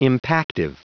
Prononciation du mot impactive en anglais (fichier audio)
Prononciation du mot : impactive